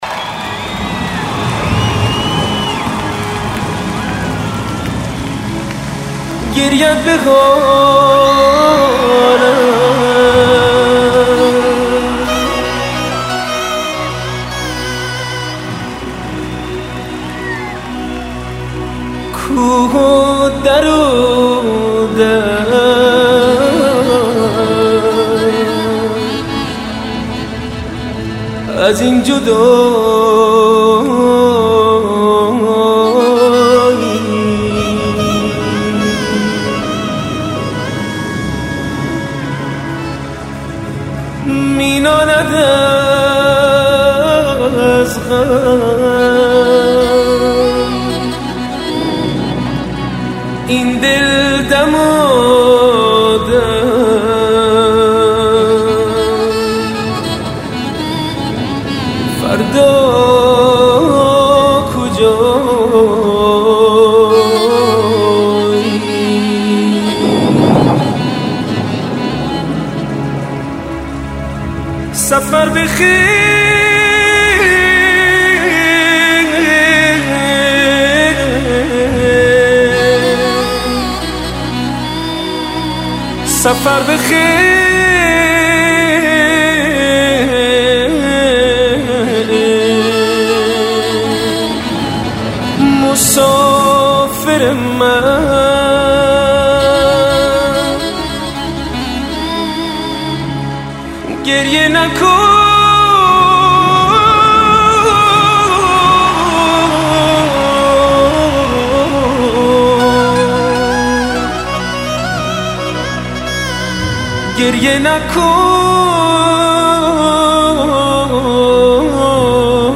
ژانر: پاپ + رپ
توضیحات: بهترین اجرای زنده در کنسرت های خوانندگان ایرانی